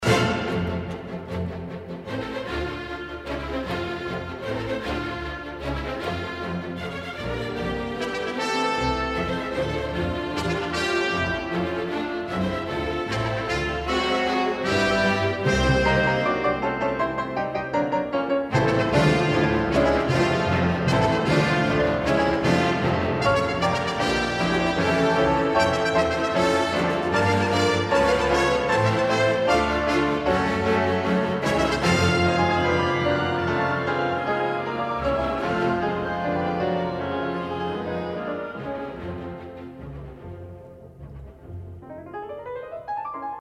原速度，先乐队后钢琴进行强有力的进行曲格式变奏